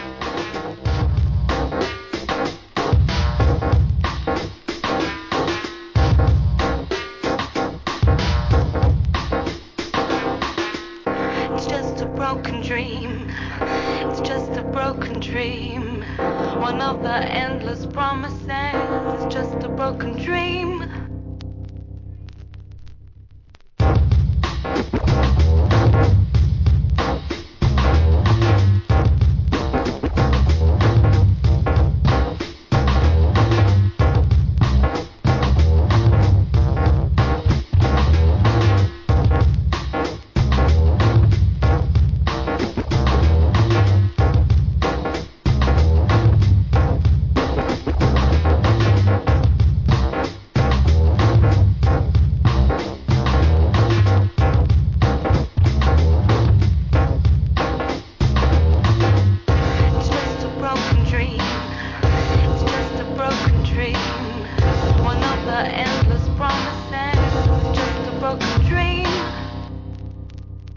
HIP HOP/R&B
良質ABSTRACT HIP HOP満載!!